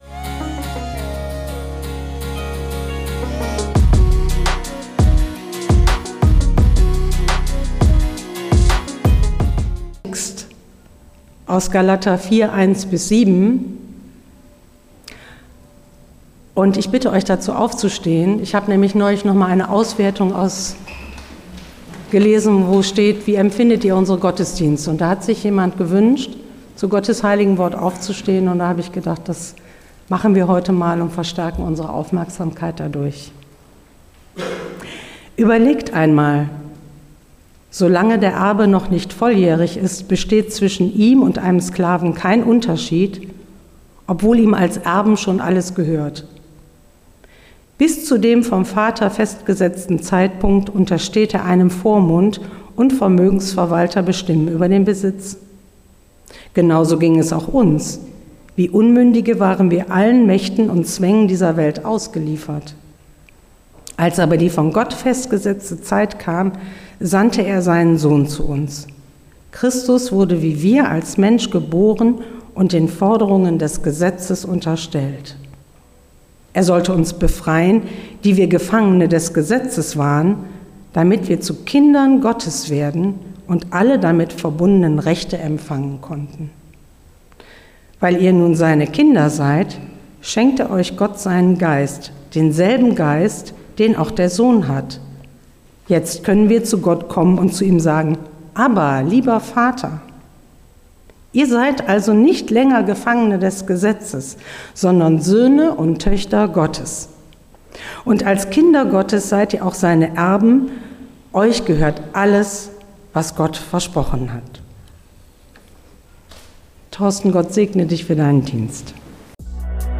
Predigt in der Freien evangelischen Gemeinde Wuppertal-Barmen am 5.5.2024.